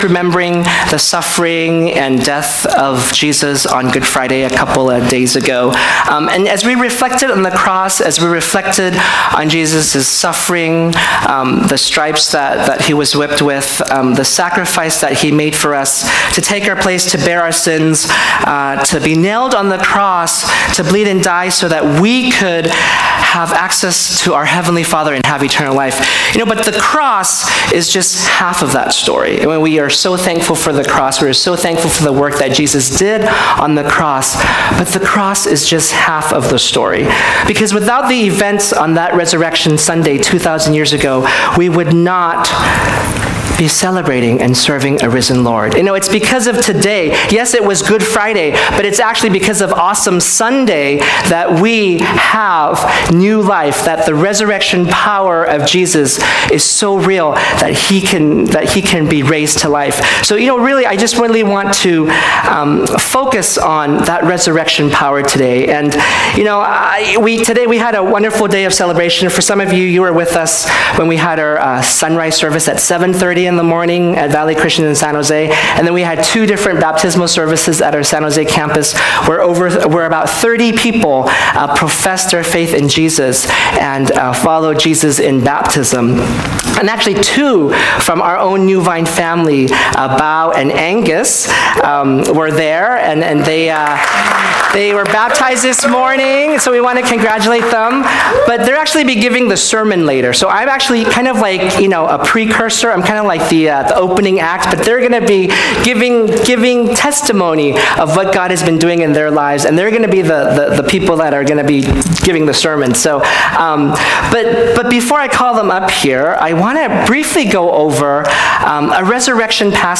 Recent Sermons